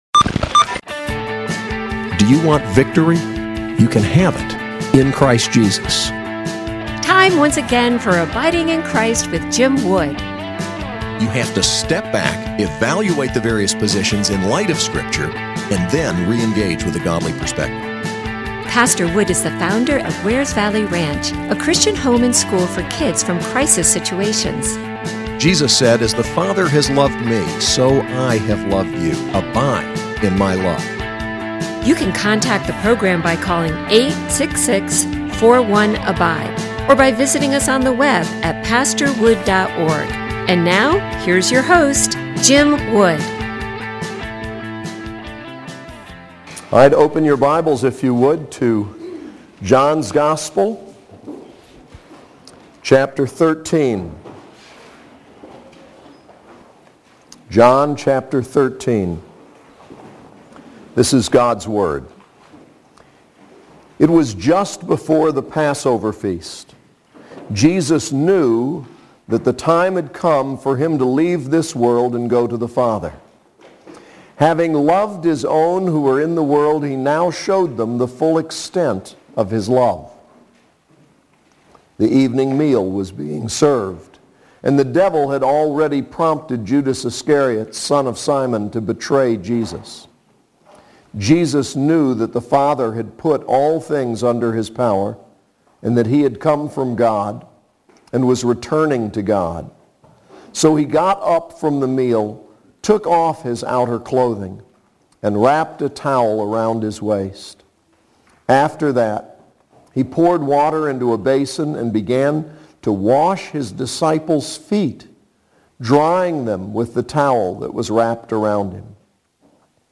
SAS Chapel: John 13:1-30